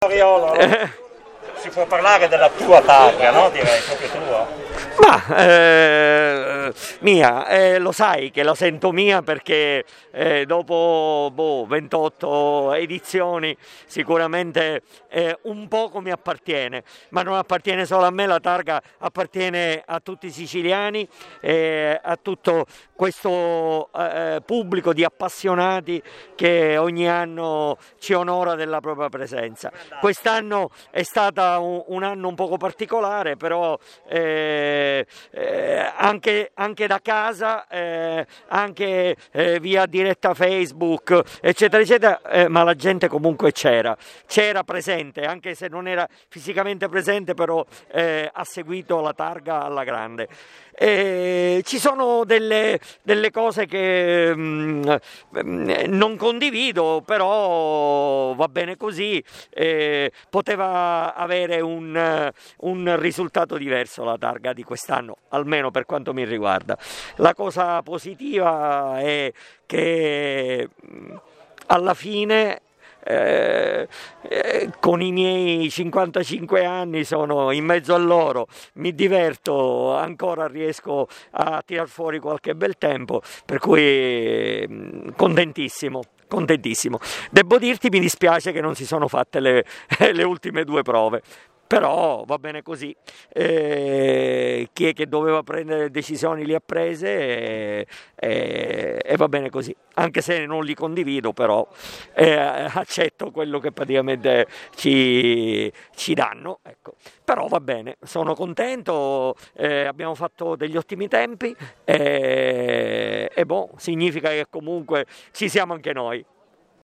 Interviste Rally Targa Florio 2020
Interviste di fine rally